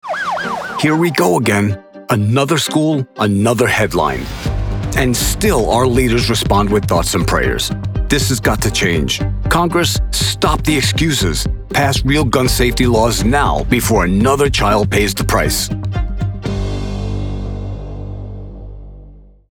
Political Voice Over for Democratic Campaigns
Deep, authoritative, and credible — the voice your audience trusts before the message registers.
The read has to respect the weight of the topic without becoming heavy-handed.
Custom-built isolation booth, Sennheiser MKH 416, Audient id14mkII interface, Studio One Pro with iZotope RX. Broadcast quality on every take.